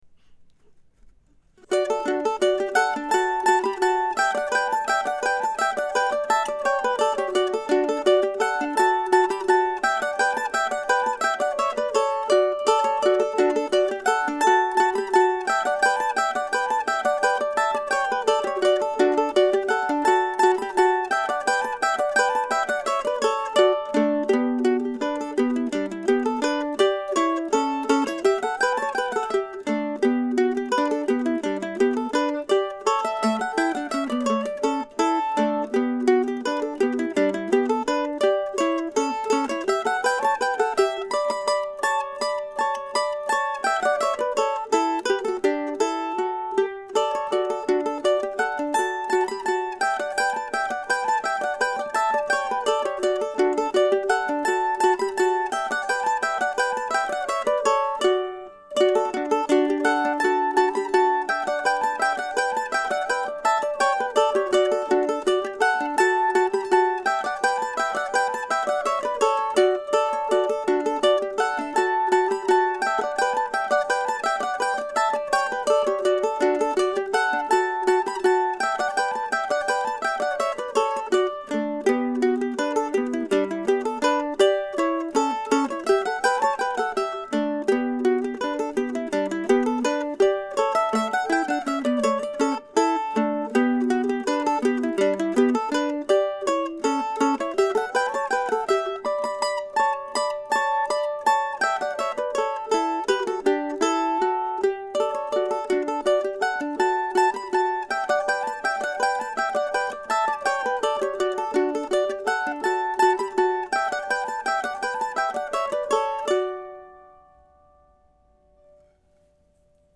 Casselmanduo.mp3